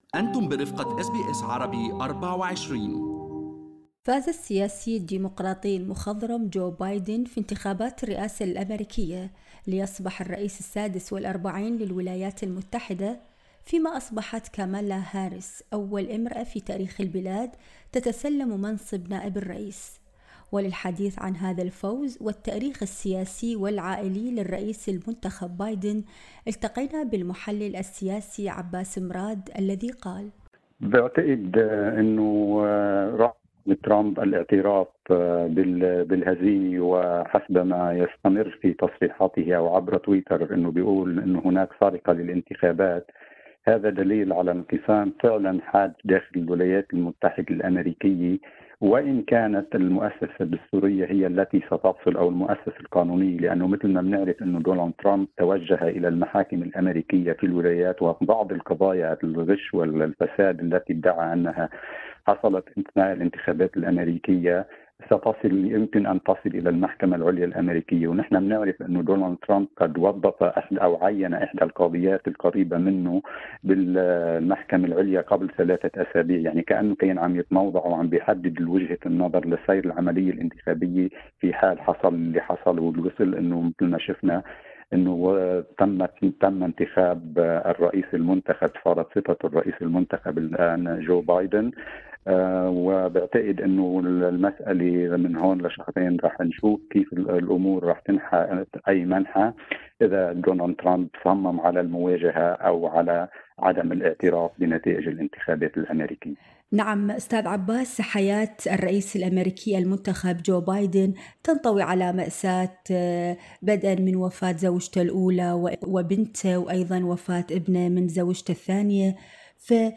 في حديث لإذاعة اس بي اس عربي 24